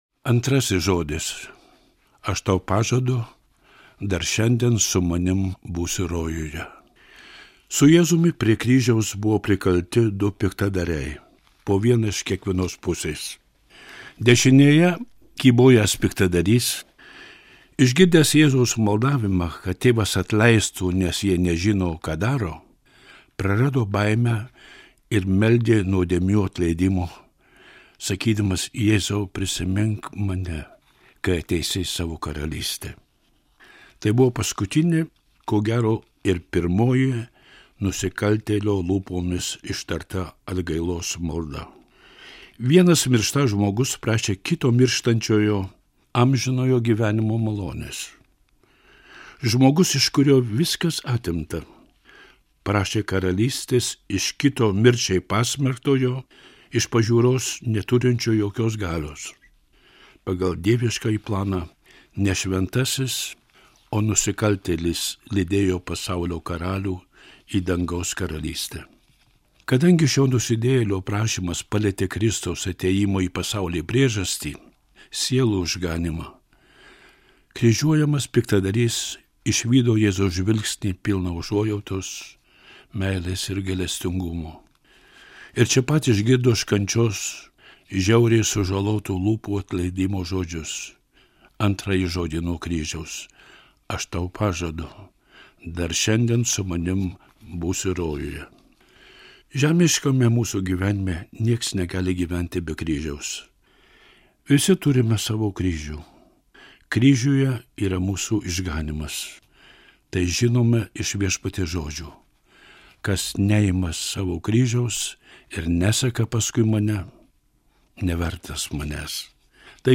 skaitovas / narrator